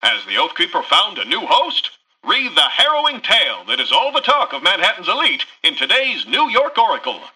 Newscaster_headline_13.mp3